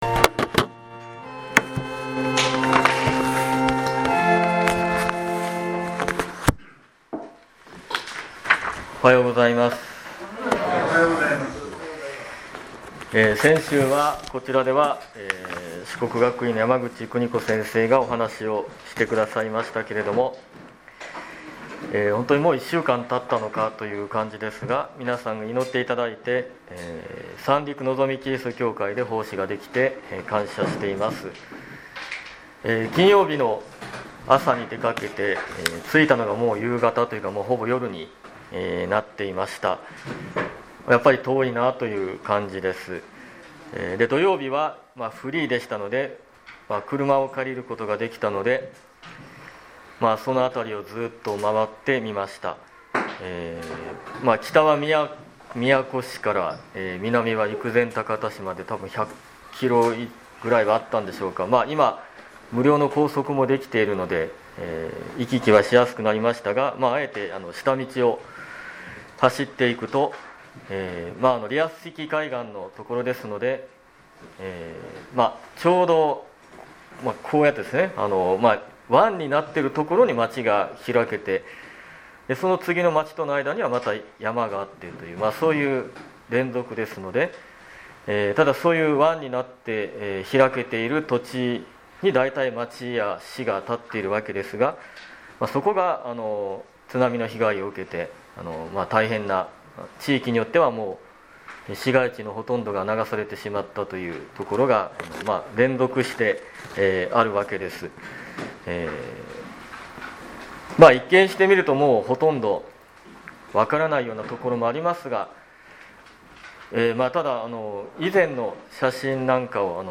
2019年11月3日礼拝メッセージ